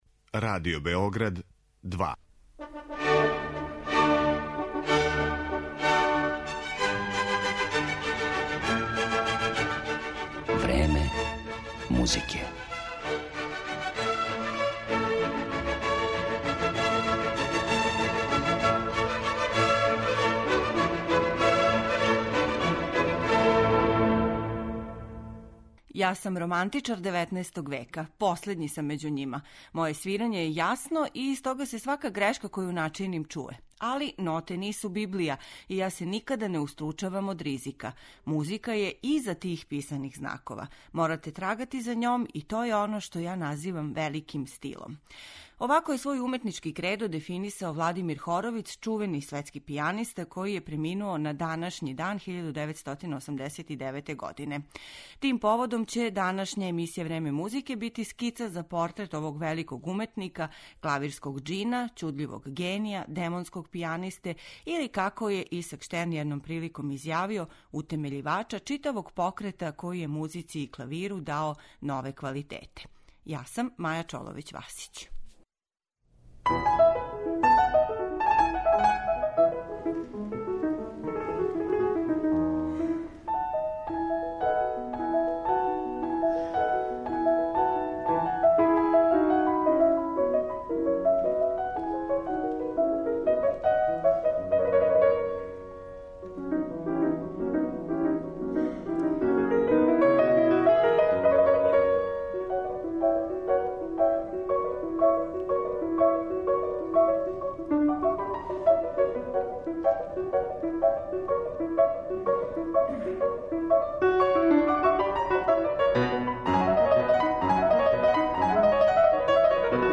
Представићемо га делима Скарлатија, Шопена, Листа и Рахмањинова.